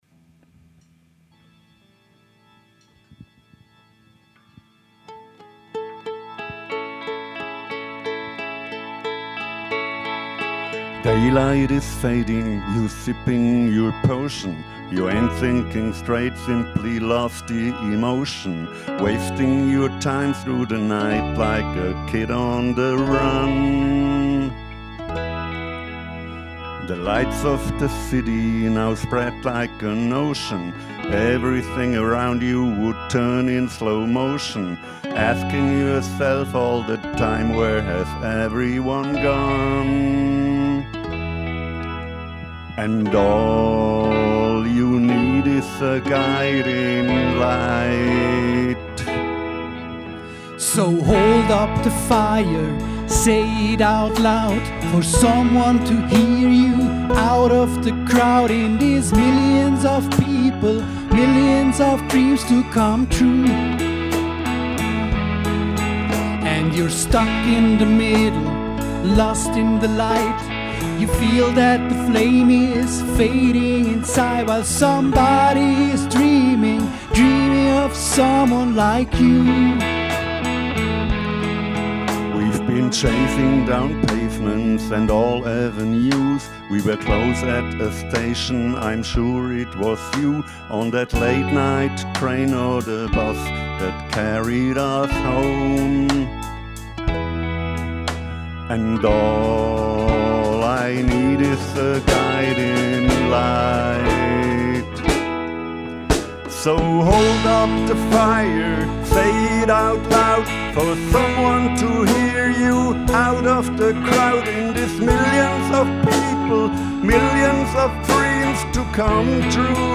Unsere erste Coverversion